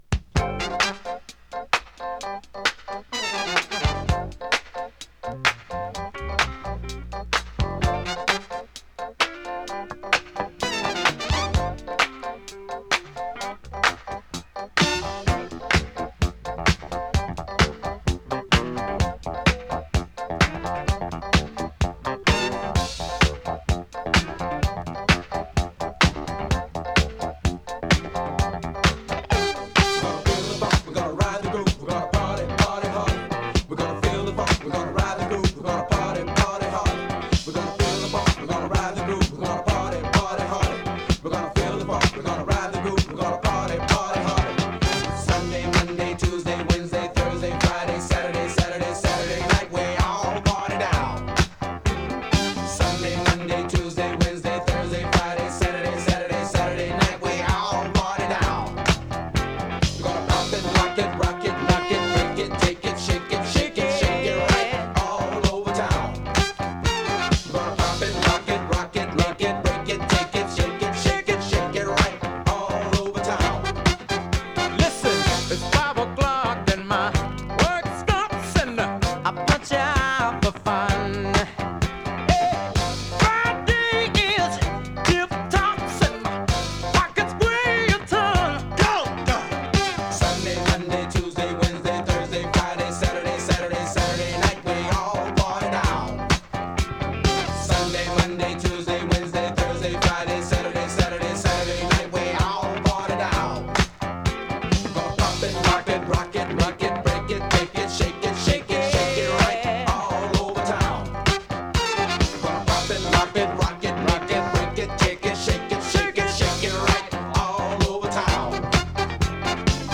キャッチーディスコ ファンク ダンクラ 哀愁メロウ AOR